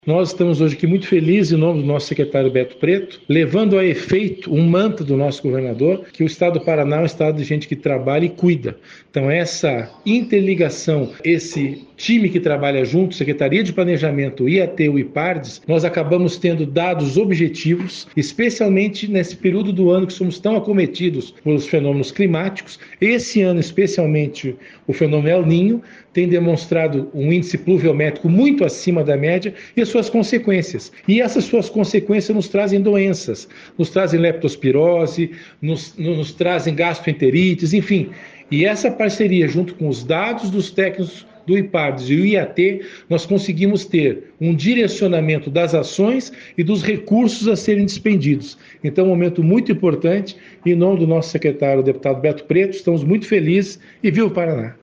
Sonora do diretor-geral da Secretaria da Saúde, César Neves, sobre a solicitação ao Banco Mundial de R$ 45 milhões para ações do Paraná Eficiente